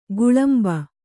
♪ guḷamba